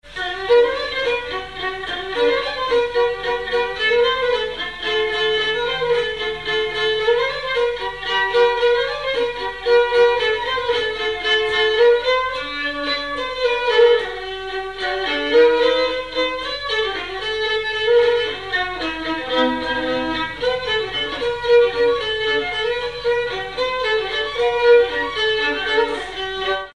Marche
danse : marche
circonstance : conscription
Pièce musicale inédite